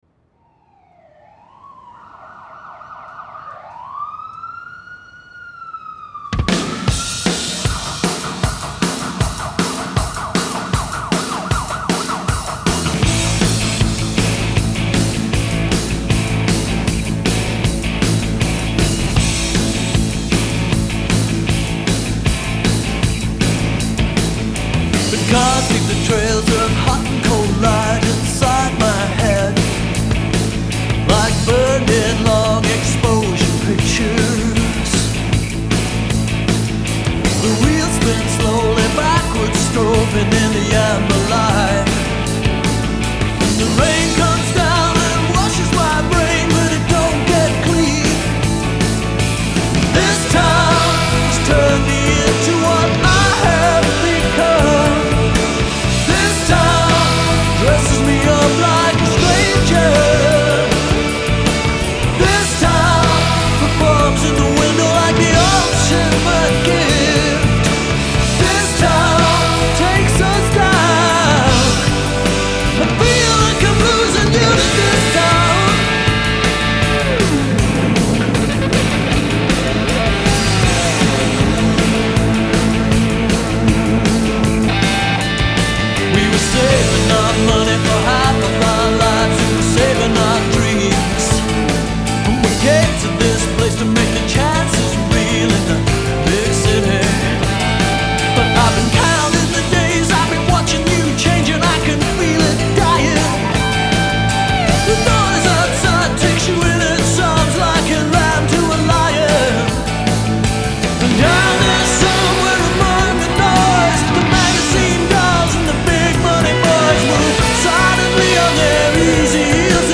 Genre : Progressive Rock